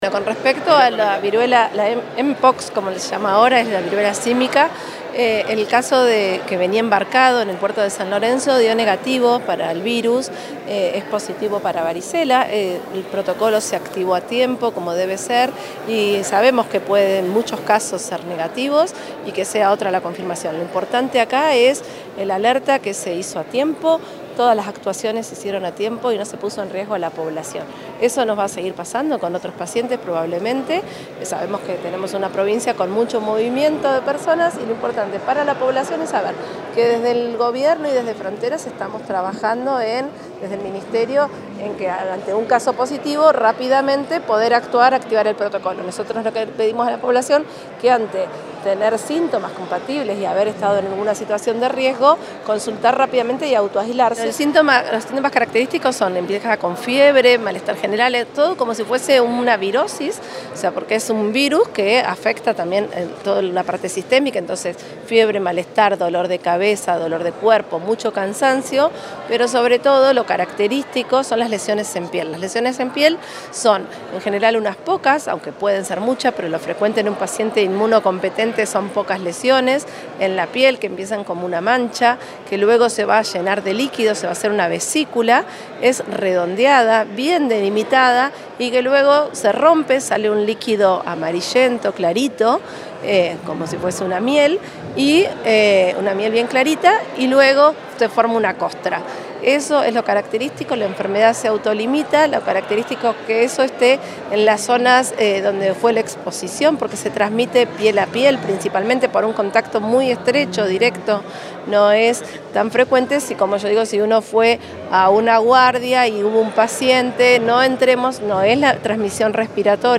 Lo confirmó la directora de Epidemiología provincial, Carolina Cudós, en rueda de prensa.